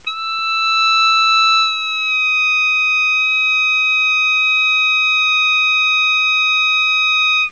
Bend8 sustain
bend8sustain.wav